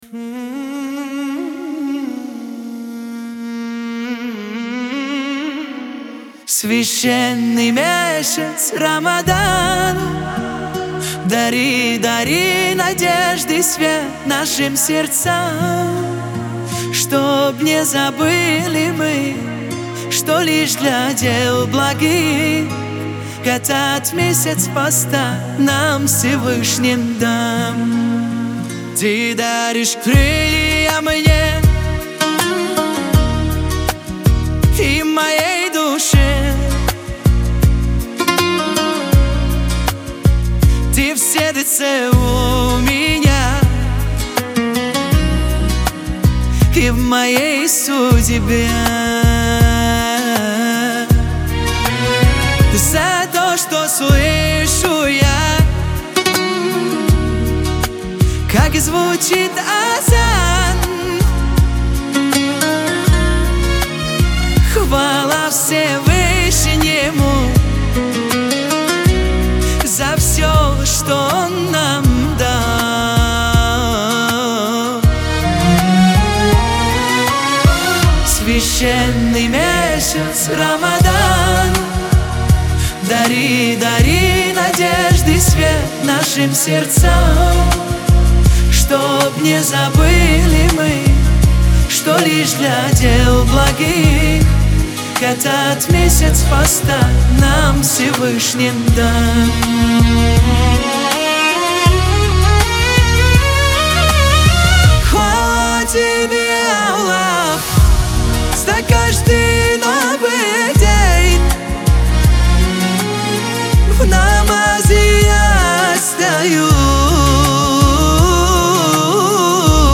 Трек размещён в разделе Рэп и хип-хоп / Русские песни / Поп.